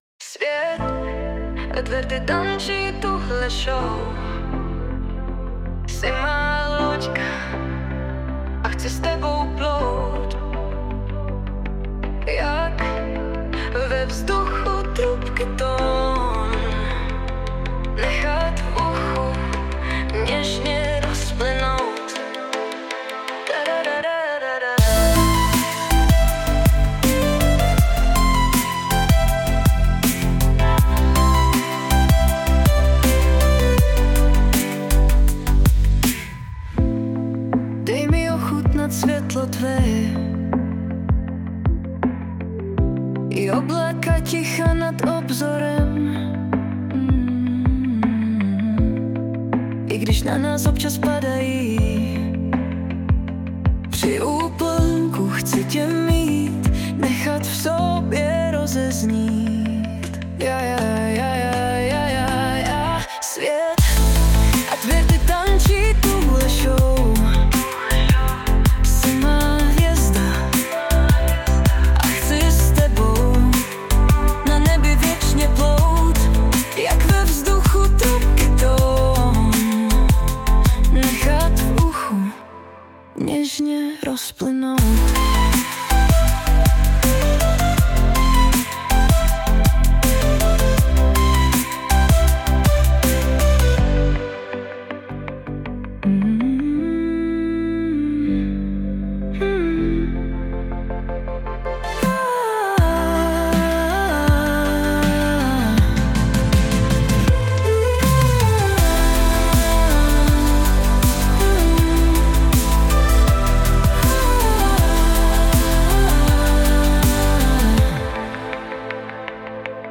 Velmi něžné